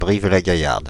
Brive-la-Gaillarde (French pronunciation: [bʁiv la ɡajaʁd]
Fr-Paris--Brive-la-Gaillarde.ogg.mp3